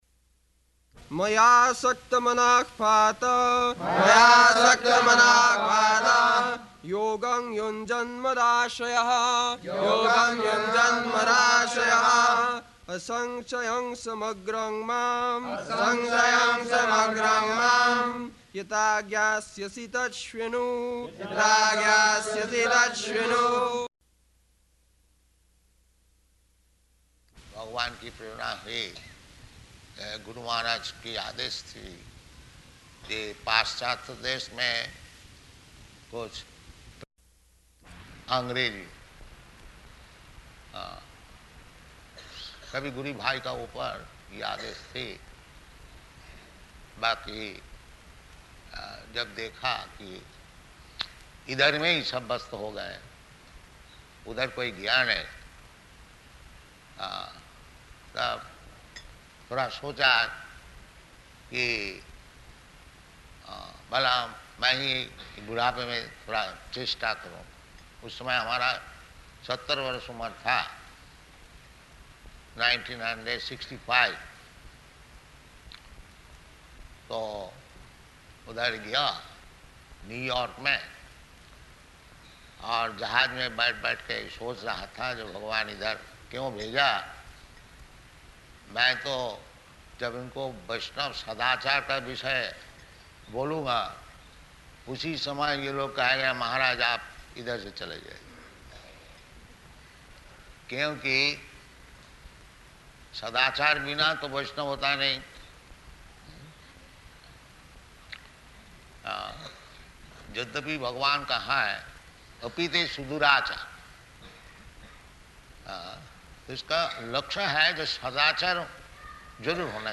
Location: Vṛndāvana
[leads chanting of verse, etc.]